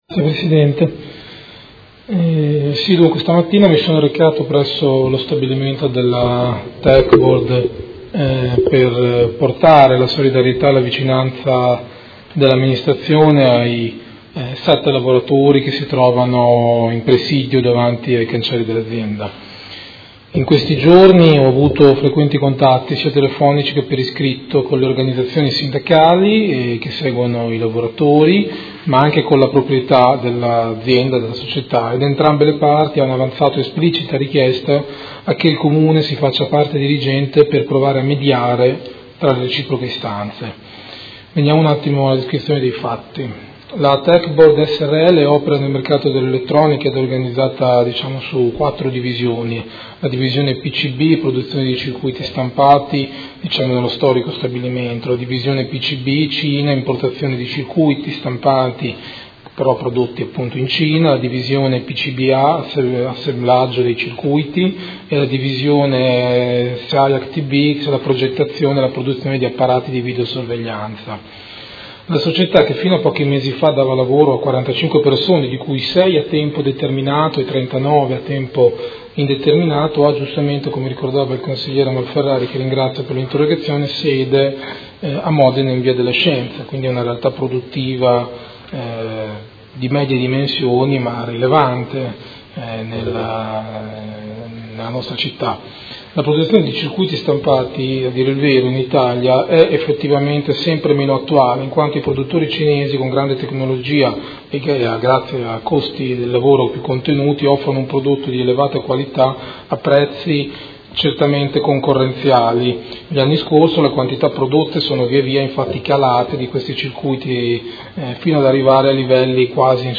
Seduta del 20/07/2017 Risponde. Interrogazione del Consigliere Malferrari (Art.1 – MDP) avente per oggetto: Techboard Srl, lavoratori da mesi senza reddito